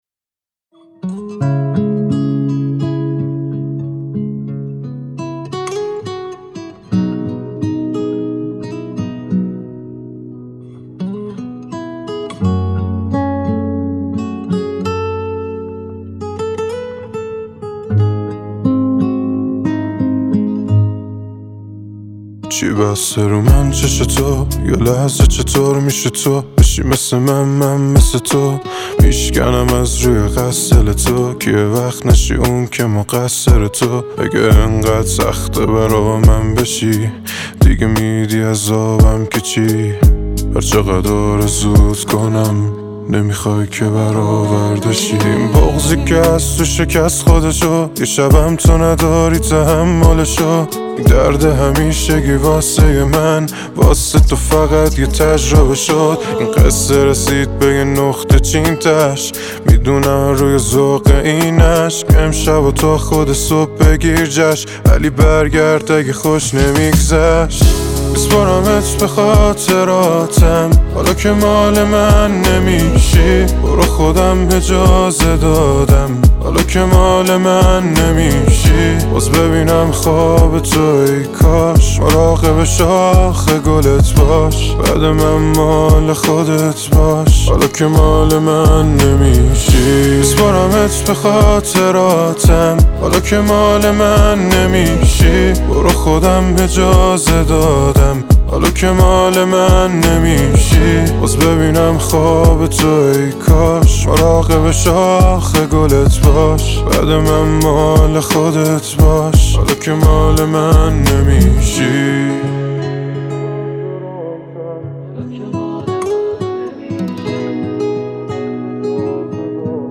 با صدای گرم و پر احساسش